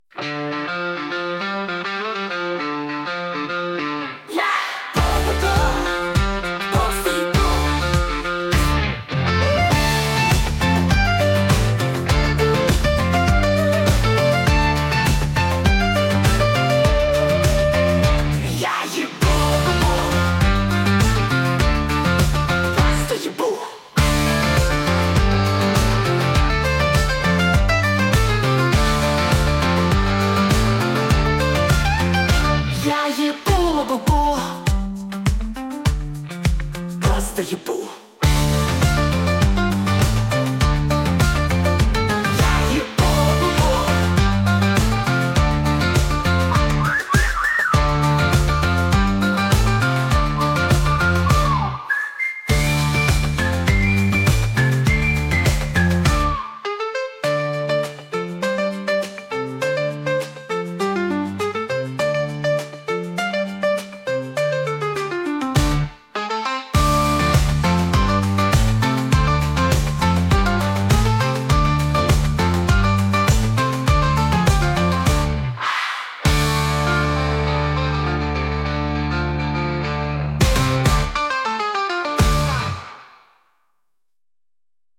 веселая песенка